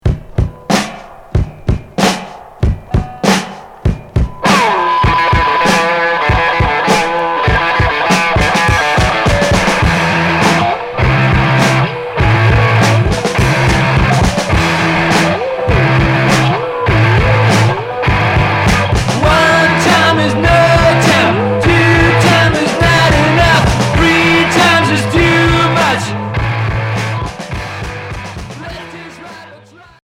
Garage punk Cinquième 45t retour à l'accueil